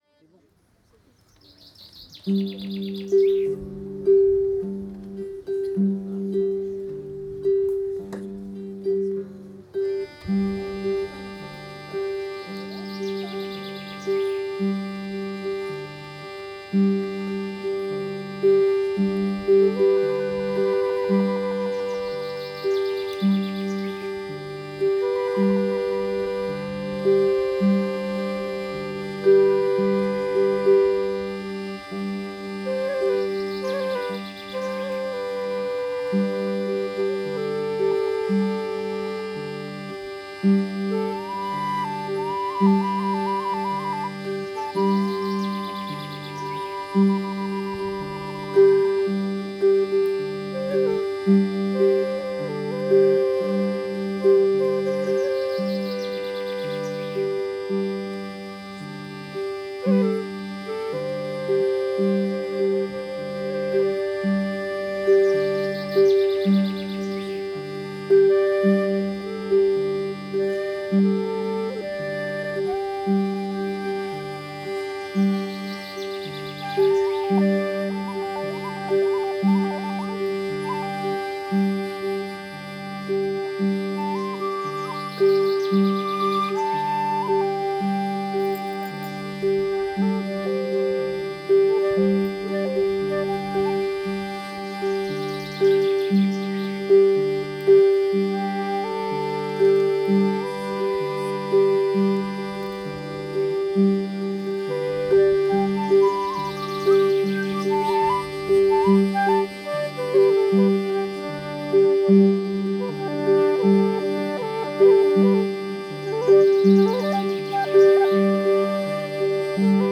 03_danses_improbables.mp3